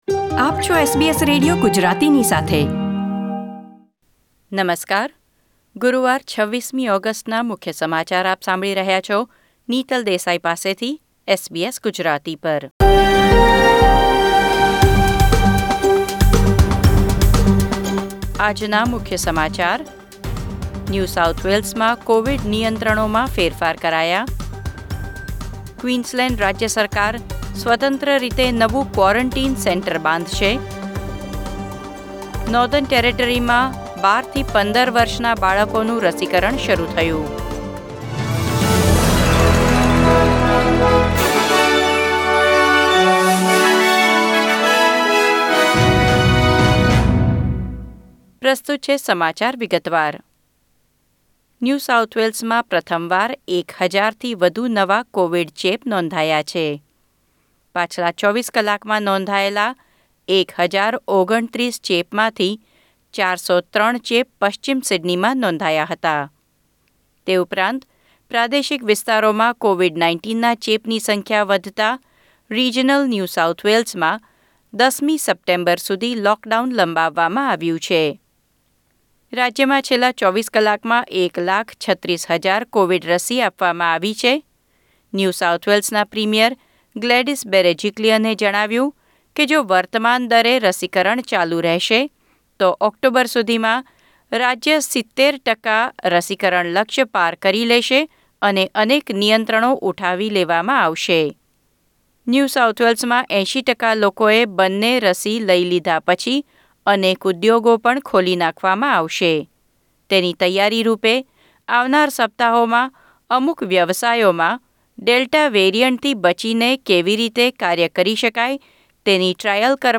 SBS Gujarati News Bulletin 26 August 2021